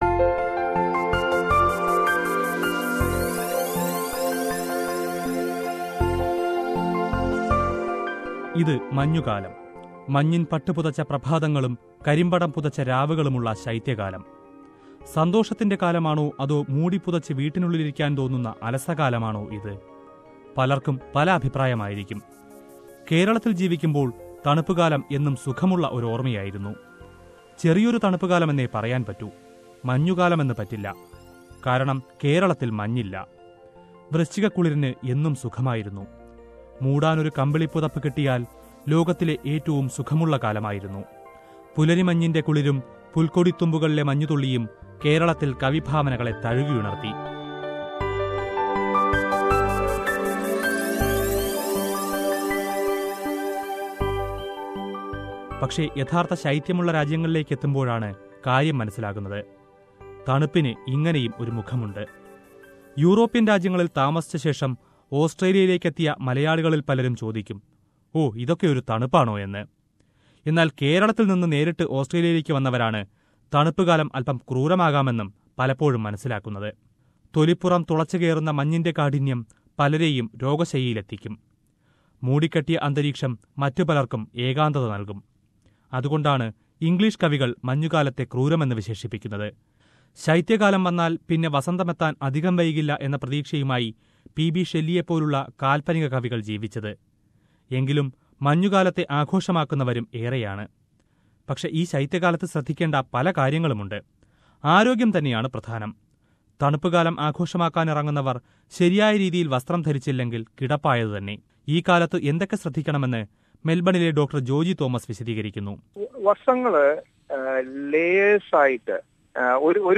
A report on the different colours and moods of winter, and advice on probable health issues